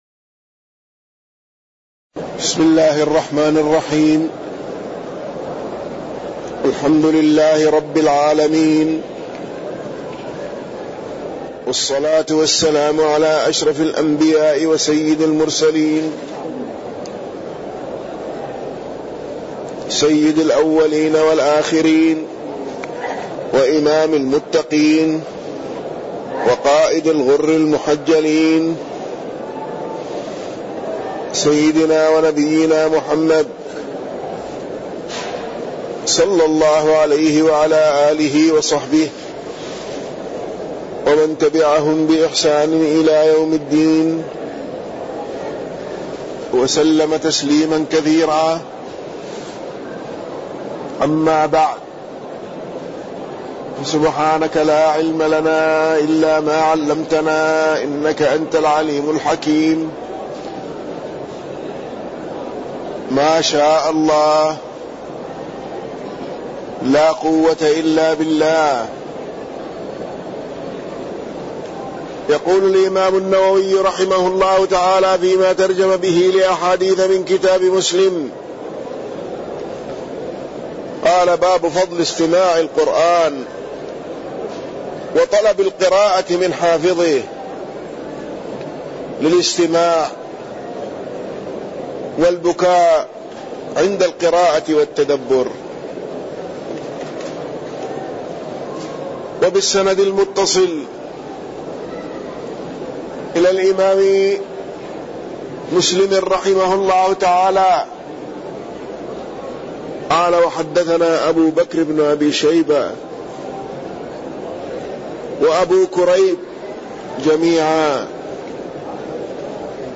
تاريخ النشر ١٤ ربيع الثاني ١٤٣١ هـ المكان: المسجد النبوي الشيخ